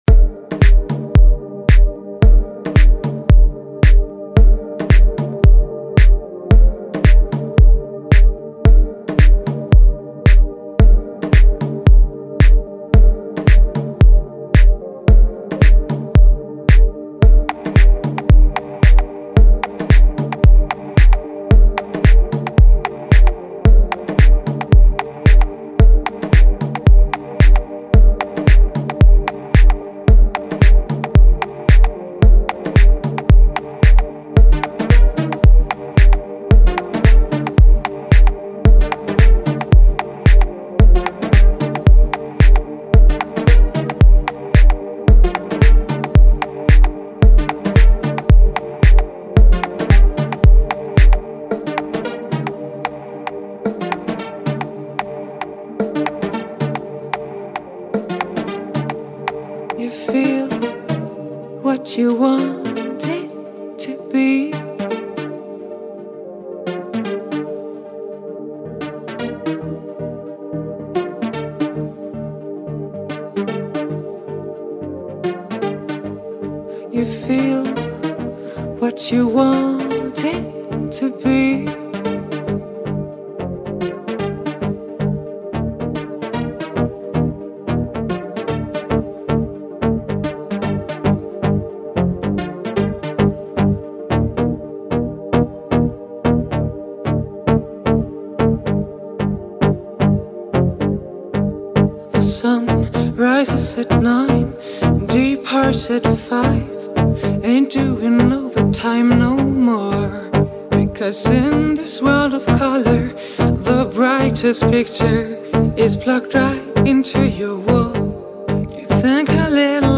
Slowed + Reverb TikTok Remix Version